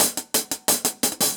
Index of /musicradar/ultimate-hihat-samples/175bpm
UHH_AcoustiHatC_175-05.wav